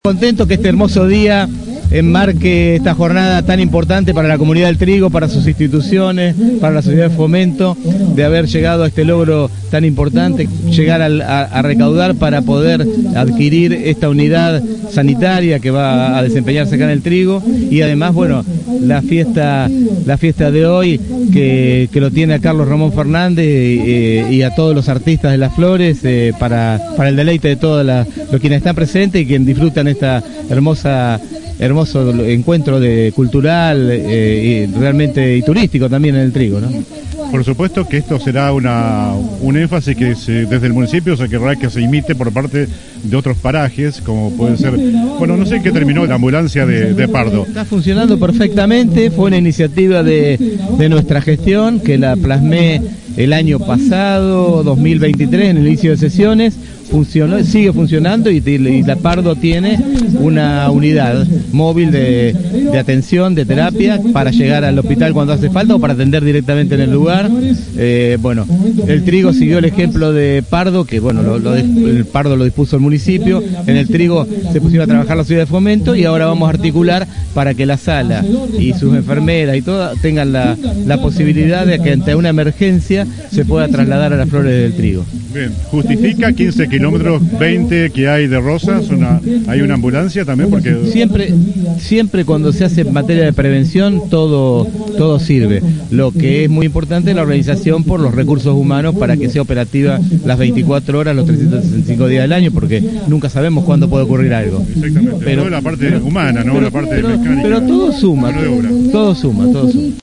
Palabras del intendente Alberto Gelené